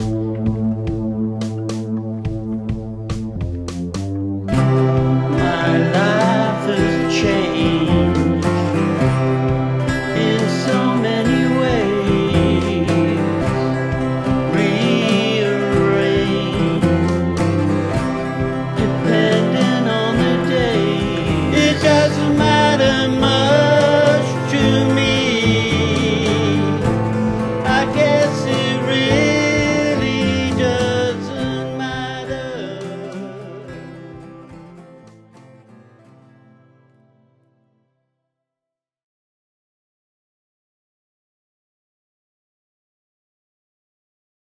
At the bottom of this short blog is an update to the home studio 40 seconds self written audio experiment on the Apple Mac M2 mini.
I just keep this little burst of sounds around.
The pads being ‘finger tapped’ result in being able to get a bit of self played percussion, singular drums, cymbals, etc. Finger tapping a basic base rhythm of drum and a ‘clapping’ beat on these little sensitive pads has allowed me to put both a simple C, F and A chord strumming sequence and a little single notes bass pattern on my Guild acoustic guitar. Layering over the rhythms. The mic is the Shure MV88 condenser for recording guitar and vocals to go over the percussion. Just found a deep piano sound and added single notes to give a bit of depth.
Just sang what first came into my head.
Nothing in this part of the process is mixed or tweaked.
So this is essentially raw. Echo on vocals, for example, has to be brought down.
So echo is a presence that isn’t welcome.
But it, the simple tune, just lolloped along as if it had just met its best friends.